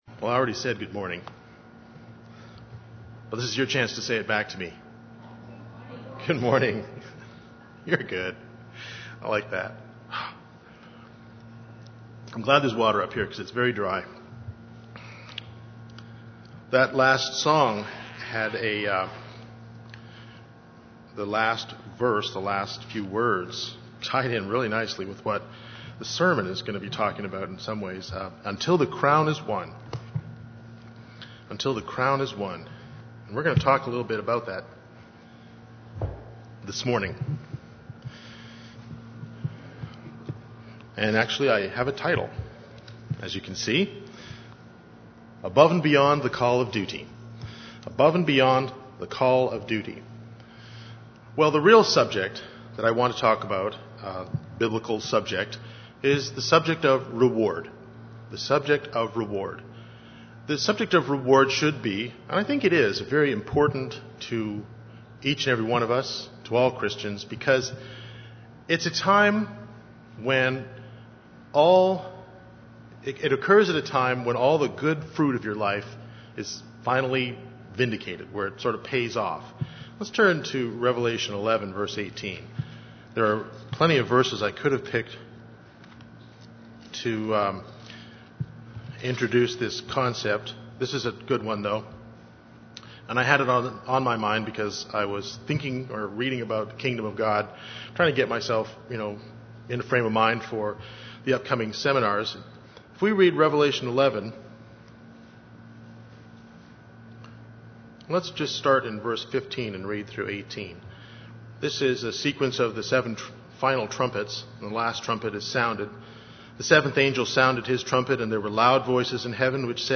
UCG Sermon Notes The subject of reward should be very important to Christians because it is the time when all good fruit of your life is vindicated.